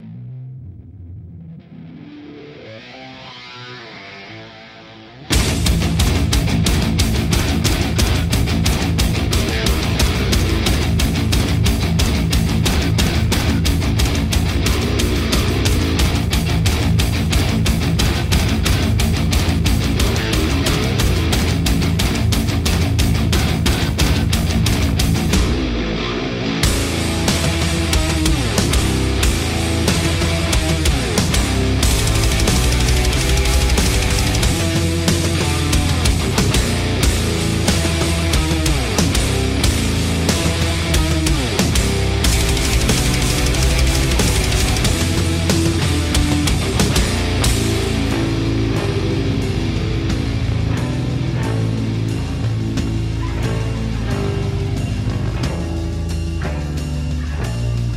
Meteor Shower Night Sound Effects Free Download